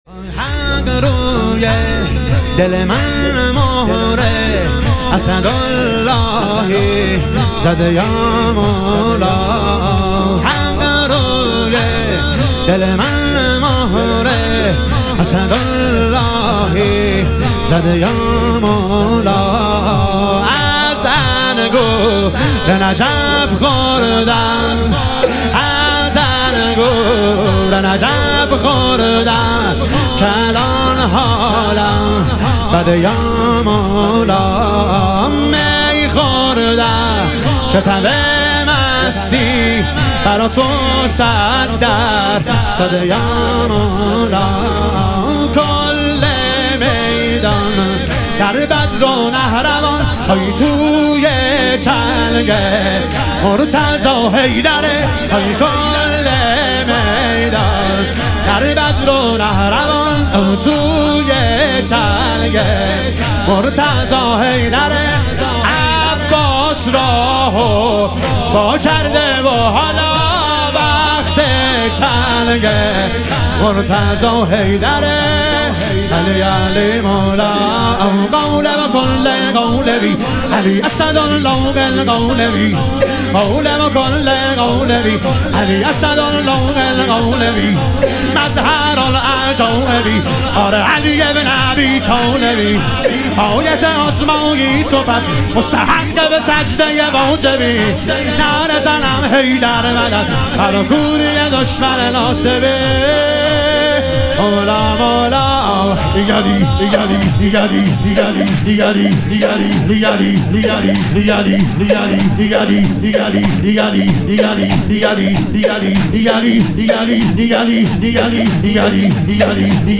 شهادت حضرت زینب کبری علیها سلام - واحد